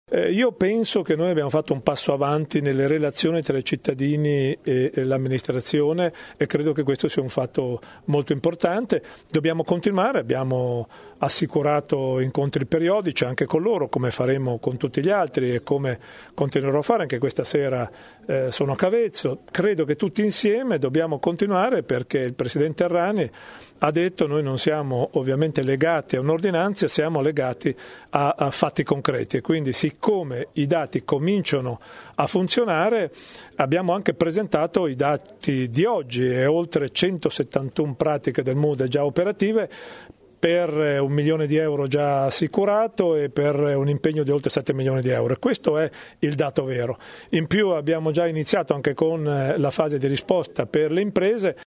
Mentre il presidente ha lasciato viale Aldo Moro per prendere un treno per Roma, l’assessore Muzzarelli ha spiegato ai giornalisti che la Regione ha intenzione di chiedere all’Agenzia delle entrate territoriale di rivedere il meccanismo degli studi di settore per le imprese che hanno avuto danni dal terremoto e ha aperto a incontri cadenzati e continui con i territori colpiti, anche per entrare nel merito dei piccoli problemi che emergono nel corso della ricostruzione.
Ascolta Muzzarelli